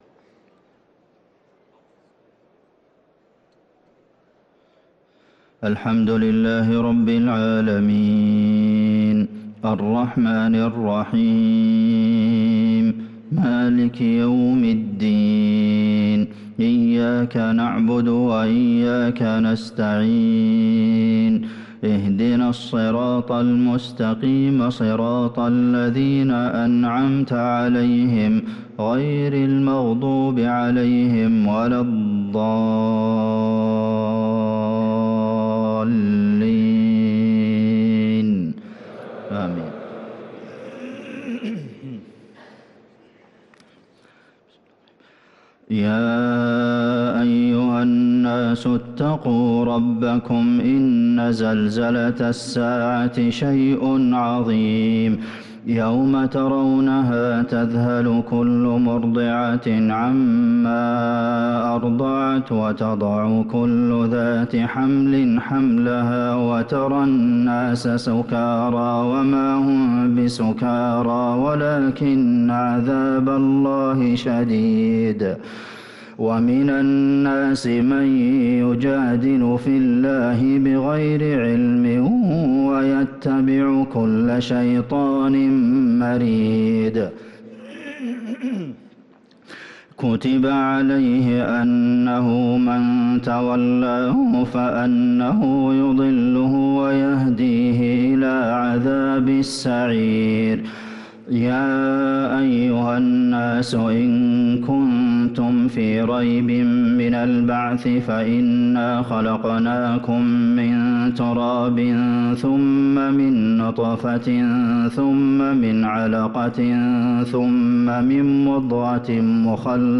صلاة الفجر للقارئ عبدالمحسن القاسم 22 جمادي الأول 1445 هـ
تِلَاوَات الْحَرَمَيْن .